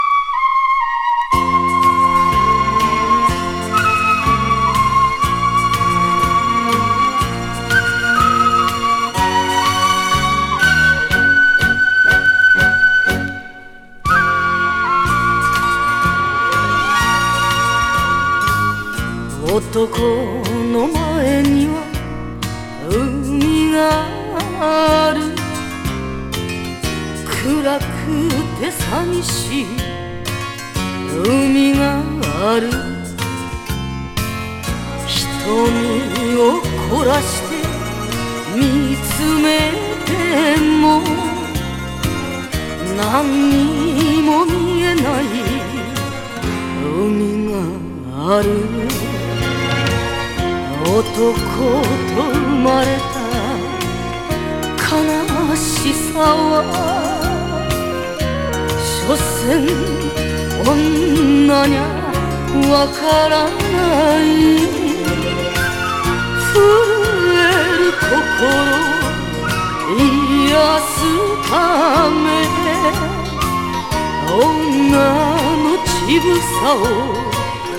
ダイナミックに始めるイントロから最高の